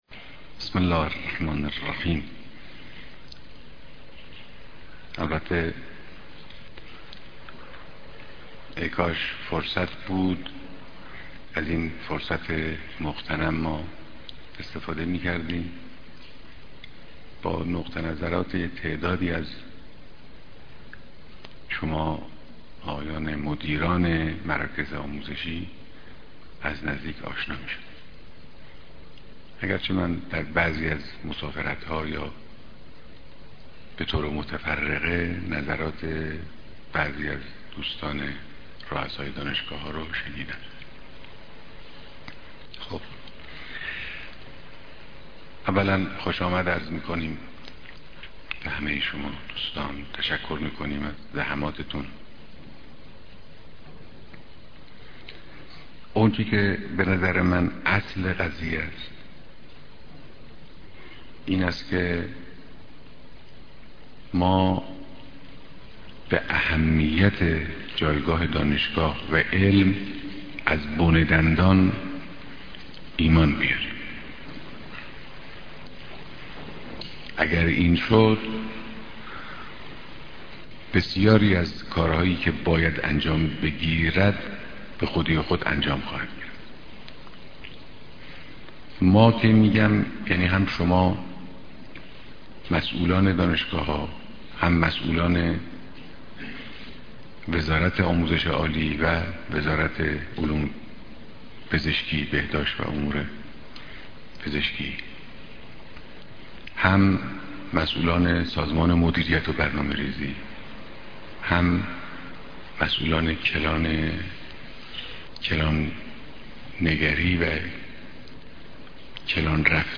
بيانات در ديدار وزير علوم و رؤساى دانشگاه ها